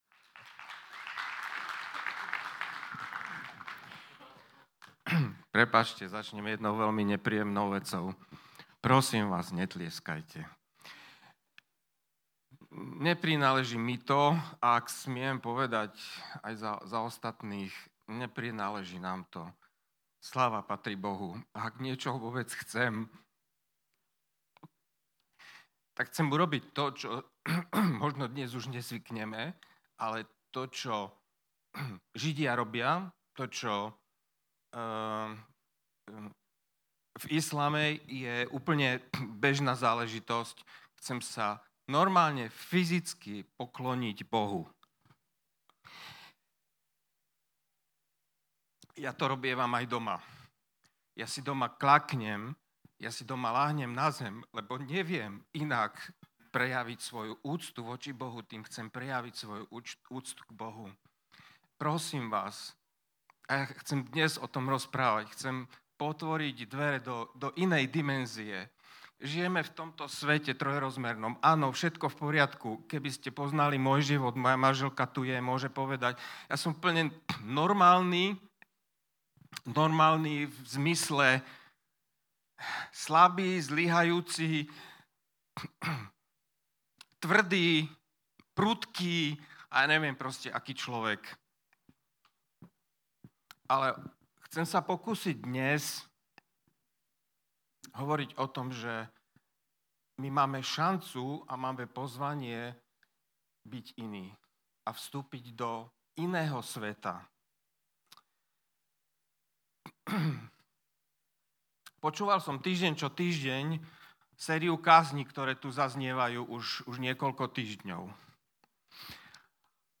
Vypočujte si túto kázeň inšpirovanú skúsenosťami z reálneho života a buďme zaskočení milosťou.